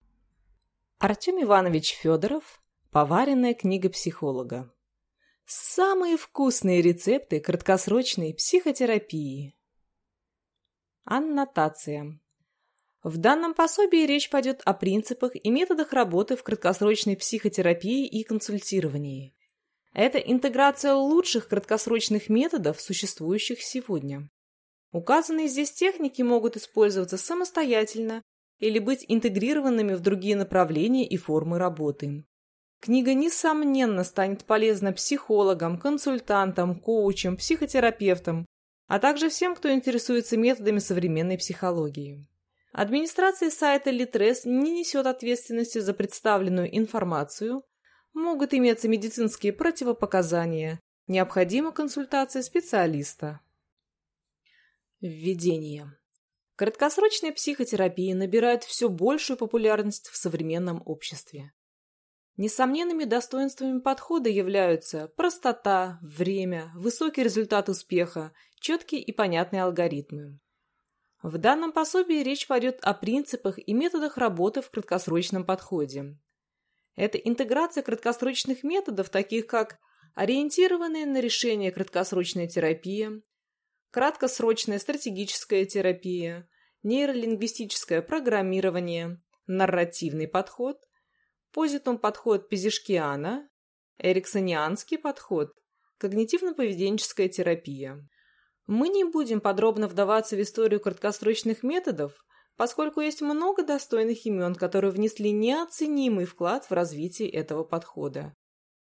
Аудиокнига Поваренная книга психолога | Библиотека аудиокниг